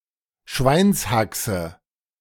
Schweinshaxe (German pronunciation: [ˈʃvaɪnshaksə]